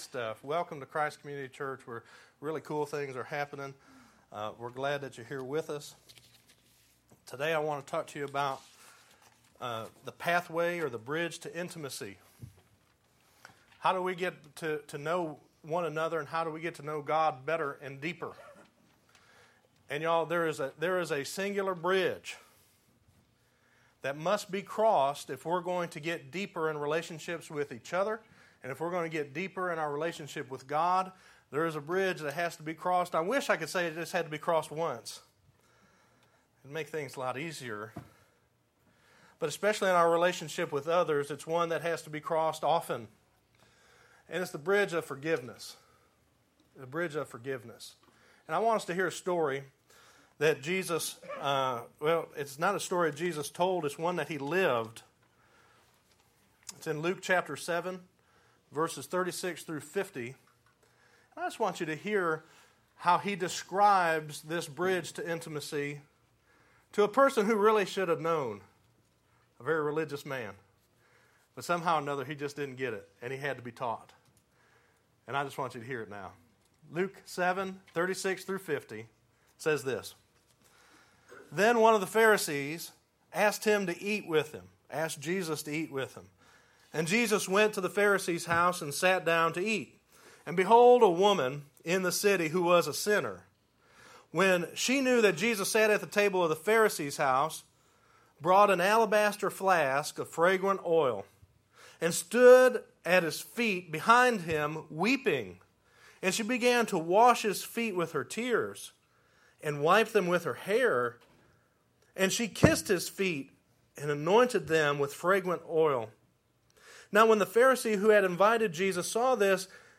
5_20_12_sermon.mp3